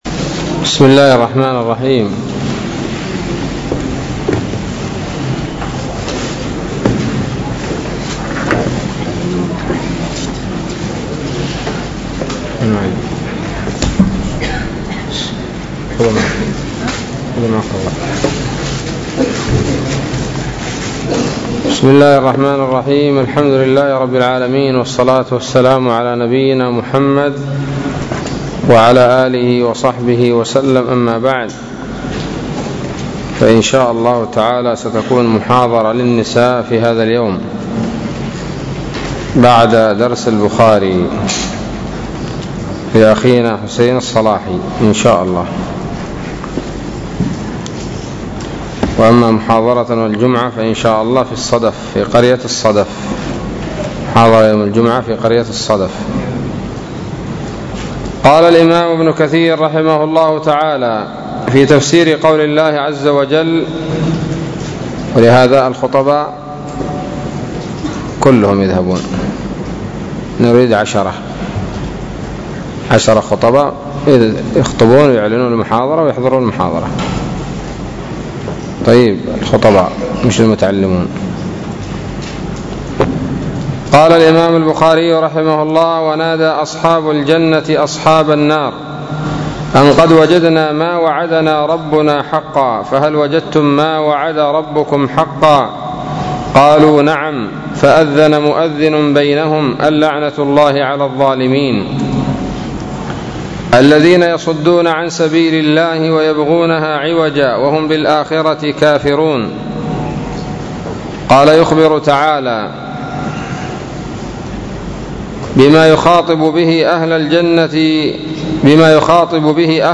الدرس الثامن عشر من سورة الأعراف من تفسير ابن كثير رحمه الله تعالى